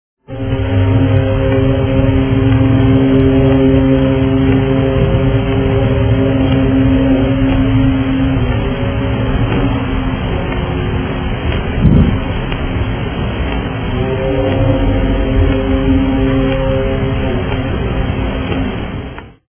横浜は一応港町です。
除夜の鐘も聞こえますが、停泊中の船の汽笛の音がしていつも新年を迎えます。